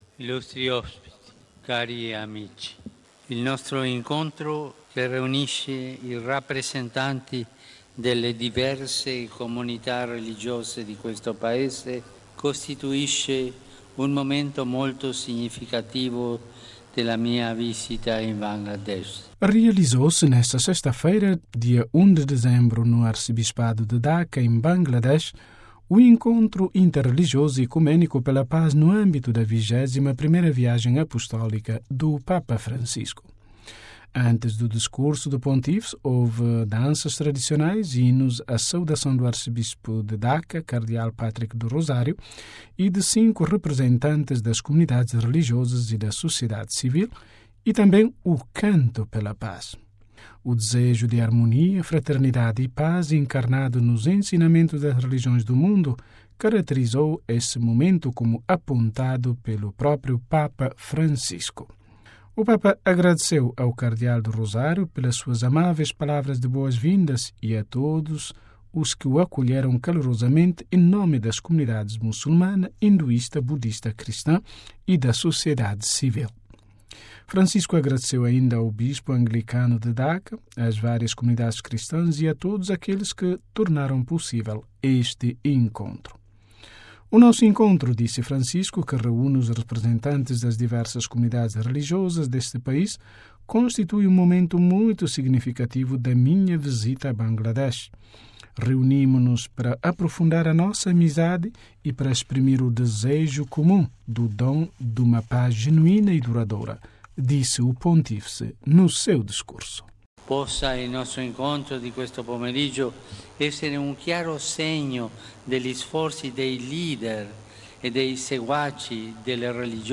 Daca (RV) - Realizou-se, nesta sexta-feira (1º/12), no Arcebispado de Daca, em Bangladesh, o encontro inter-religioso e ecuménico pela paz, no âmbito da 21ª viagem apostólica do Papa Francisco.